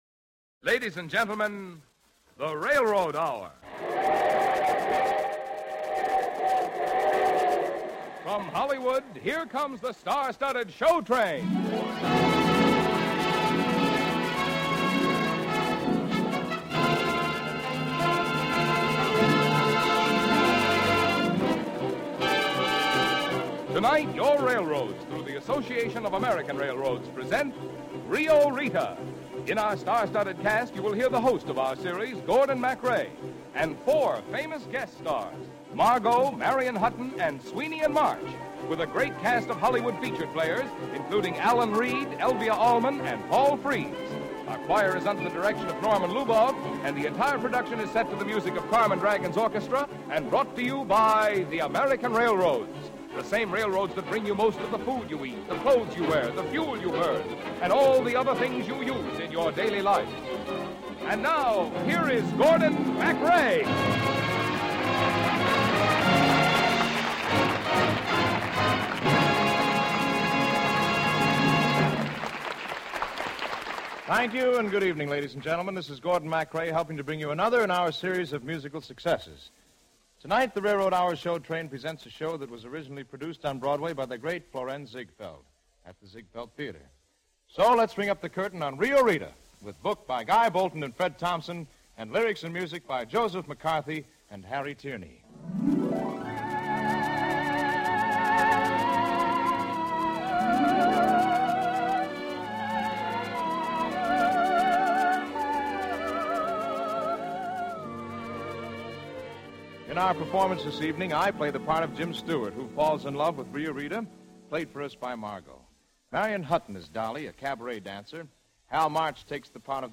radio series that aired musical dramas and comedies